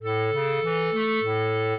clarinet
minuet7-12.wav